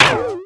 bow_dryfire_01.wav